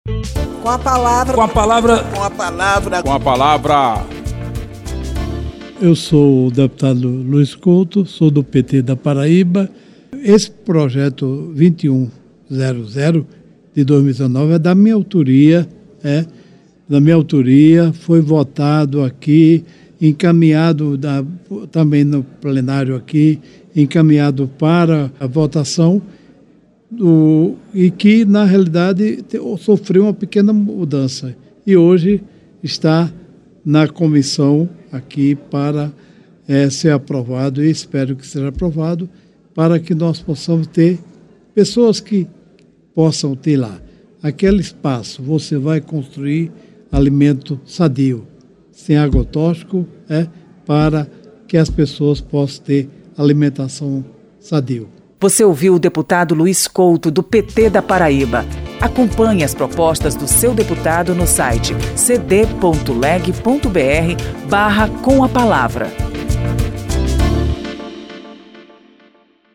O deputado Luiz Couto (PT-PB) ressalta a importância do projeto que permite a implantação de hortas comunitárias em terrenos da União.
Espaço aberto para que cada parlamentar apresente aos ouvintes suas propostas legislativas